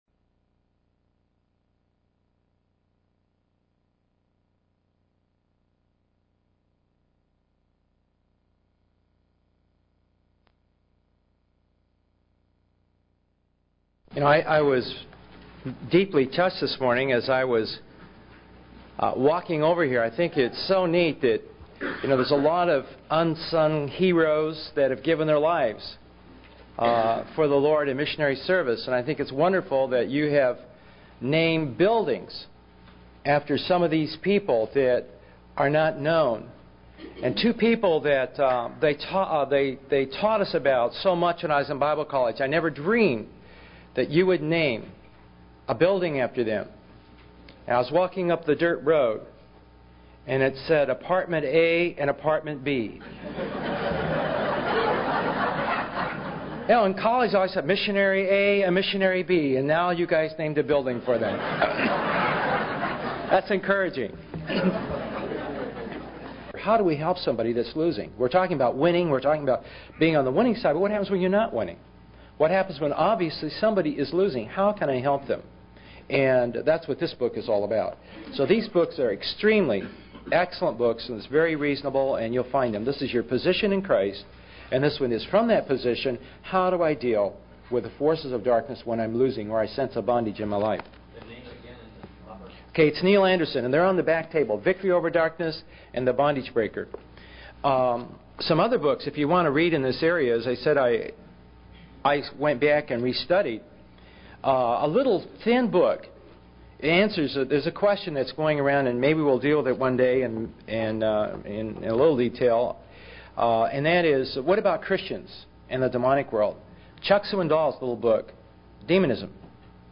In this sermon, the speaker emphasizes the importance of truth in the life of a believer. He highlights the four-fold cord of truth, which includes the person of truth (Jesus Christ), the word of truth (the Bible), the spirit of truth (the Holy Spirit), and the church as the pillar of truth. The speaker encourages listeners to put on the belt of truth every day and stand in truth to avoid being deceived by the father of lies.